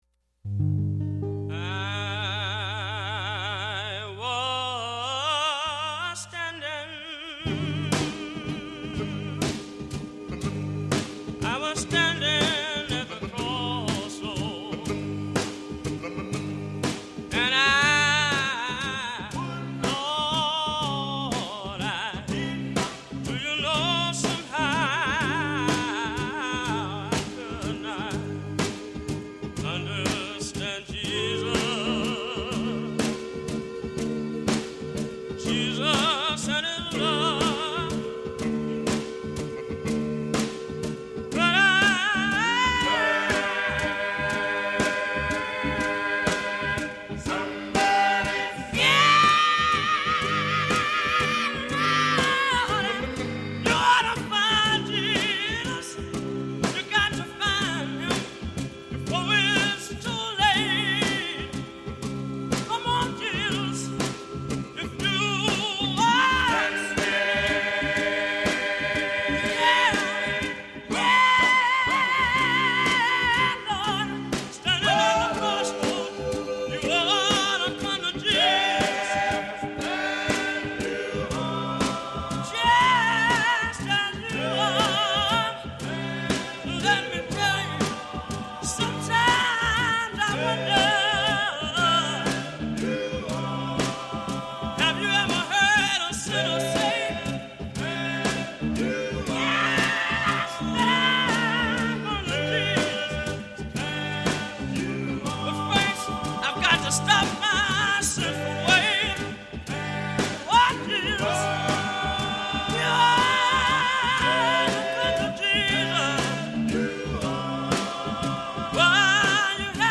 [0:07:37] Music behind DJ: [Gospel Knuckleheads are the BEST.]